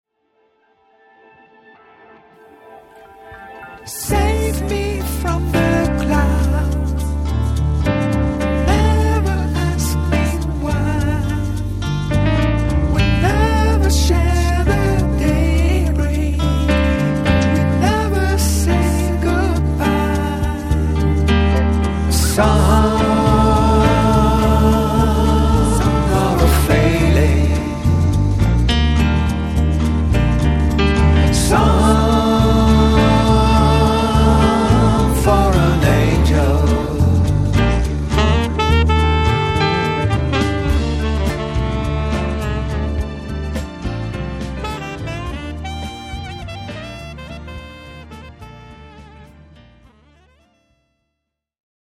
and recorded at home